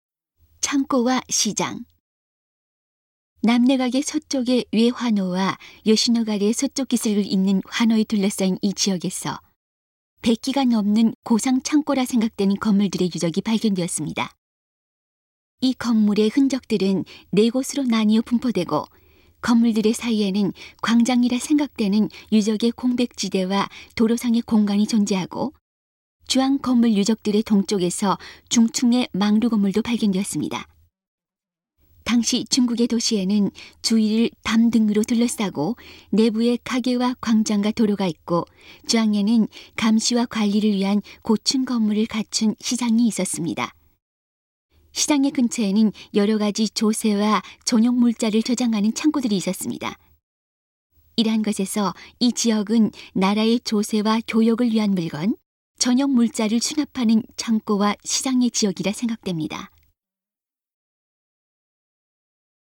음성 가이드